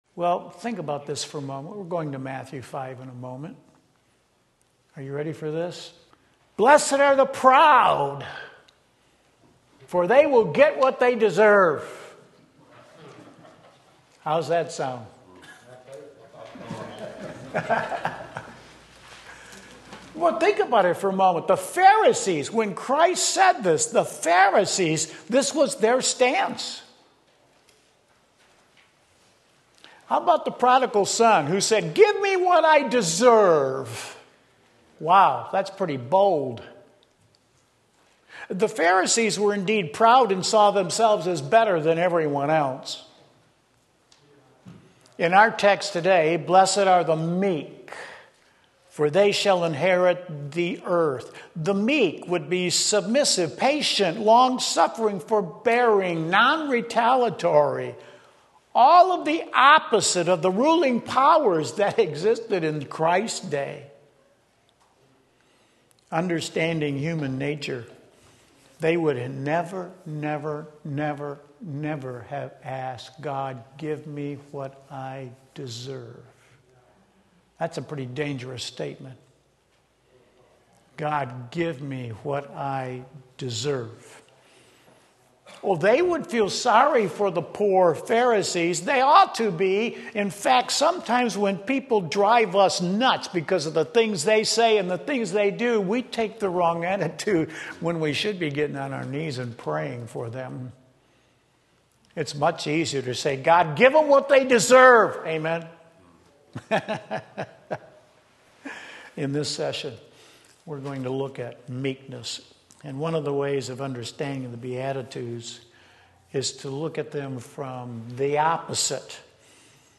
Sermon Link
Blessed Are the Meek Matthew 5:5 Sunday School